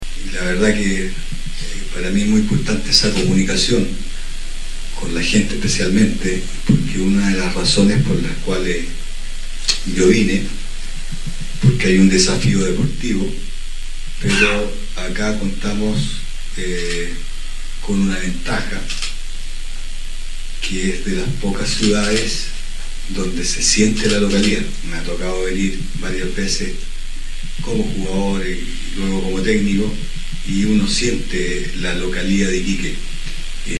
En conferencia de prensa, el técnico envió un saludo a los hinchas, resaltando la importancia de la comunicación con la comunidad y el sentido de pertenencia en la ciudad nortino.